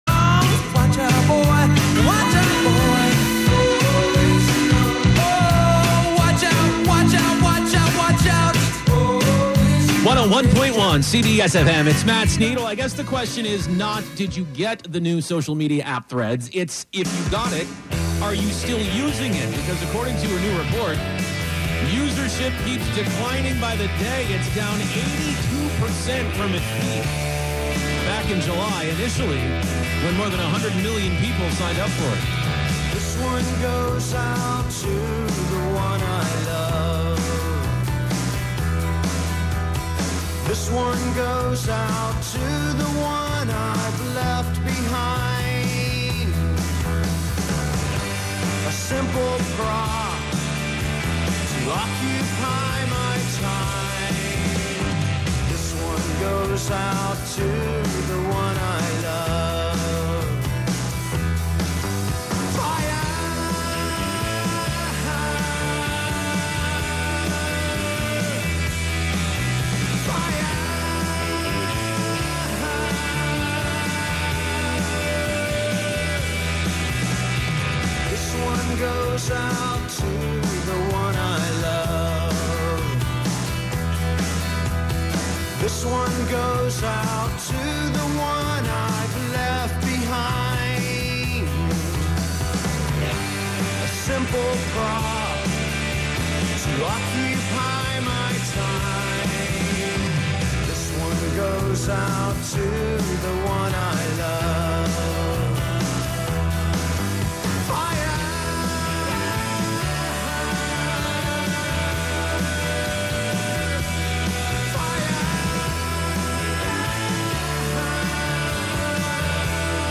11am Live from Brooklyn, New York
laptop
making instant techno 90 percent of the time